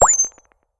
gain_xp_01.ogg